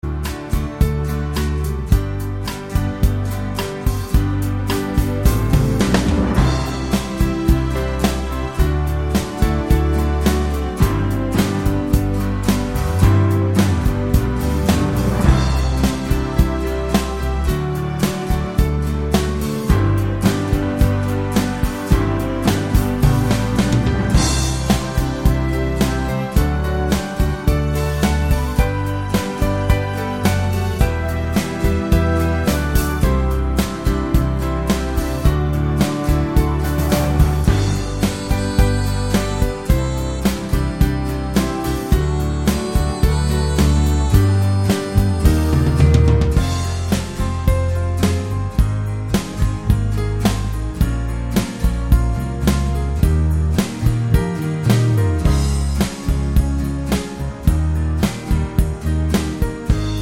no Backing Vocals Crooners 3:36 Buy £1.50